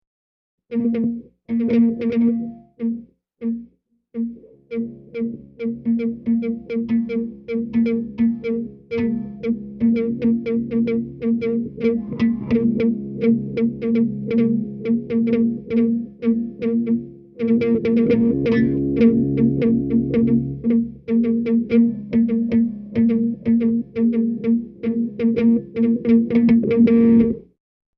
These files may be downloaded and listened to as a very minimal industrial noise album, or may be downloaded for use as above.
All files are stereo mp3s encoded at 128 kpbs, although some files are paired mono tracks, with different sources panned hard left and right.
guitar 2 0:27 paired mono 493k
guitar2.mp3